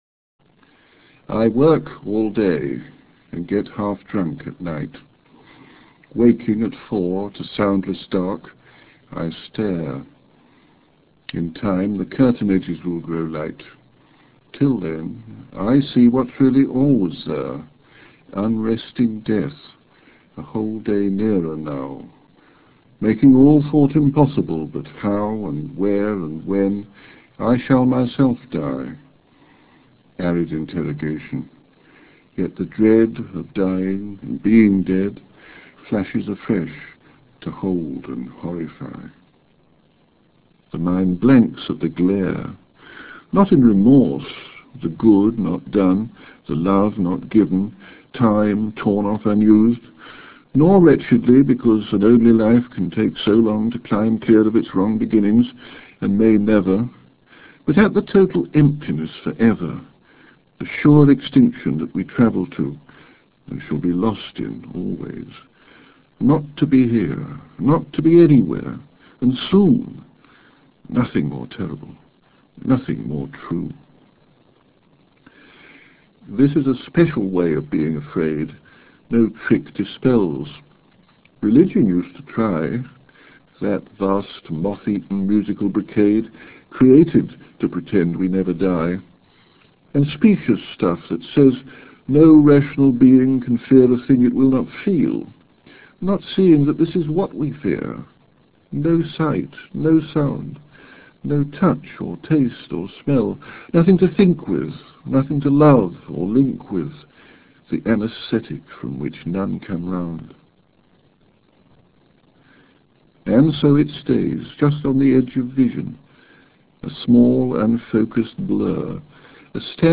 Click here to hear Larkin reading "Aubade" while you scroll through the text of the poem.